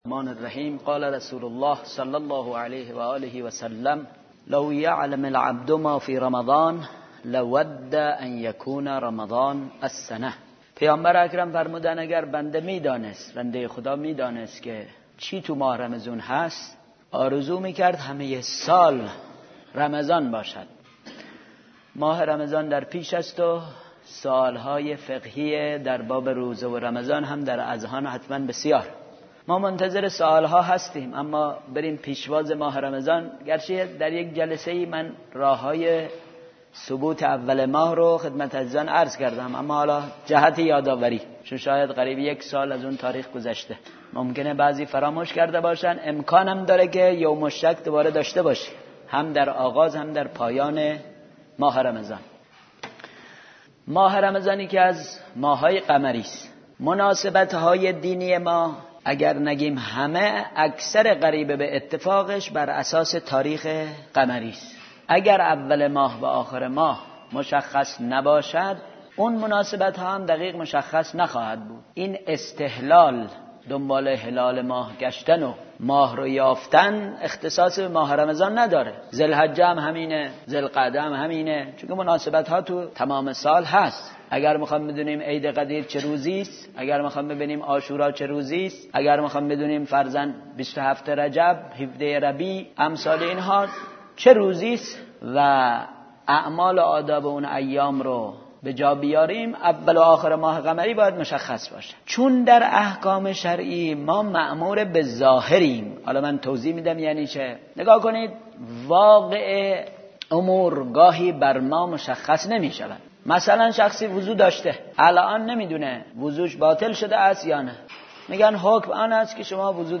سخنراني